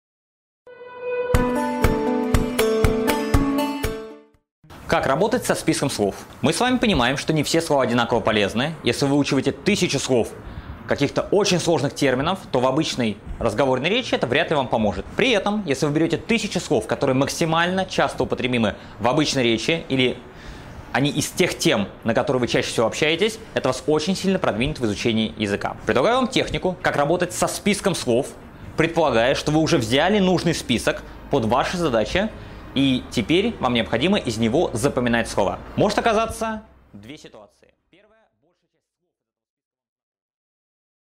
Аудиокнига Как работать со списком слов при изучении иностранного языка | Библиотека аудиокниг